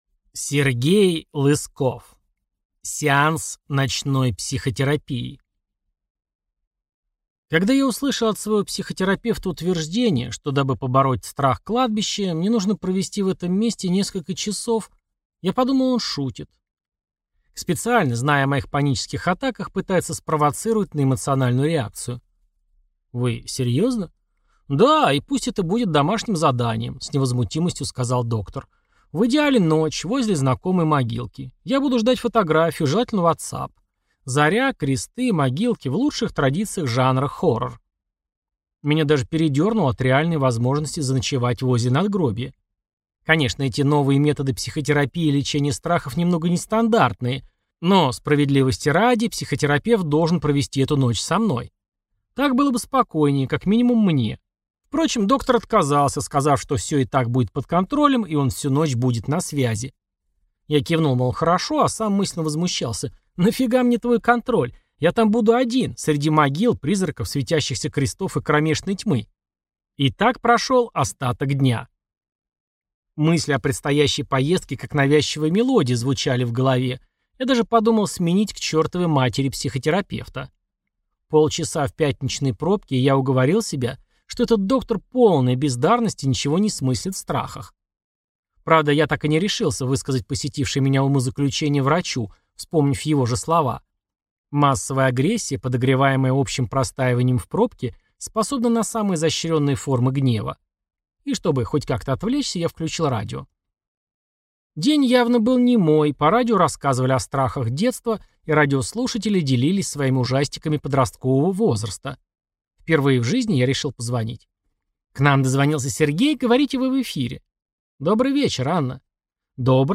Аудиокнига Сеанс ночной психотерапии | Библиотека аудиокниг